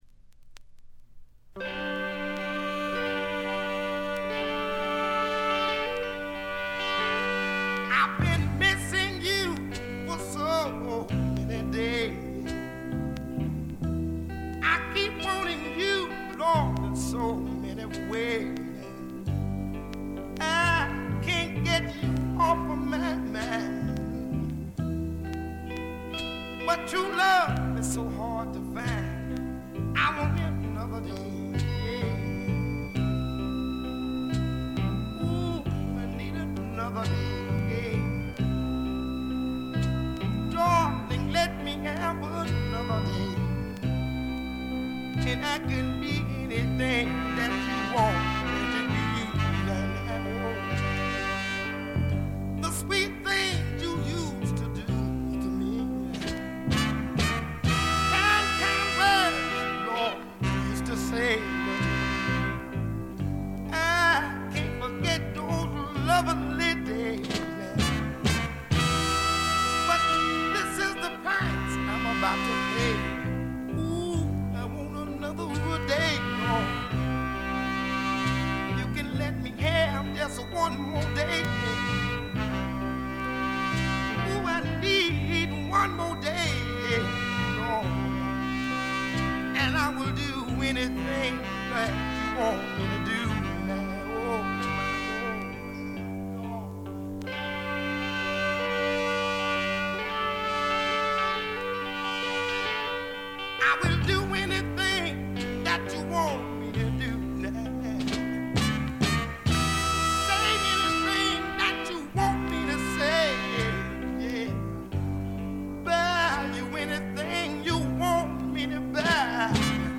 ところどころでチリプチ多め。散発的なプツ音多め。
試聴曲は現品からの取り込み音源です。